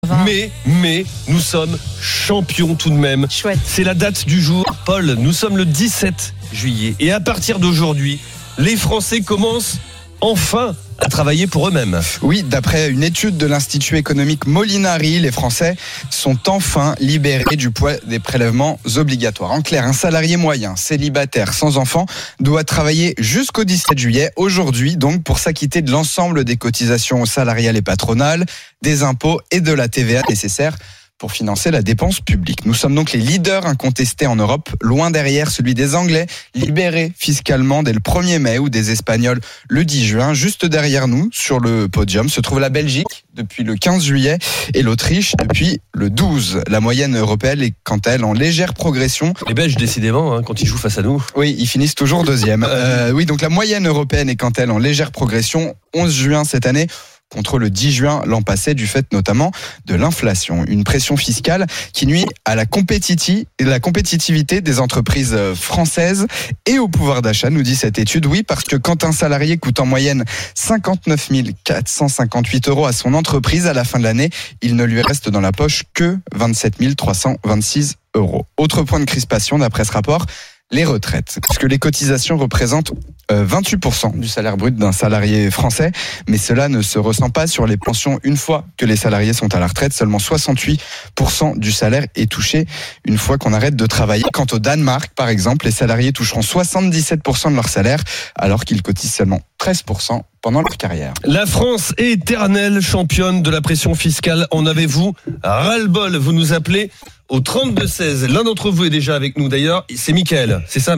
RMC, Estelle Midi, 17 juillet 2024, 13h04